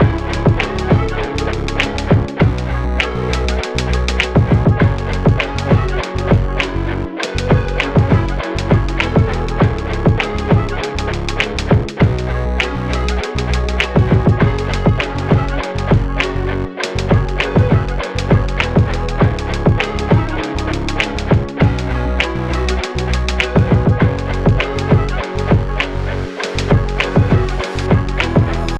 Woody Chill
Verb Distorted
Db Minor
Music - Power Chords
More Horns
Bit Sequence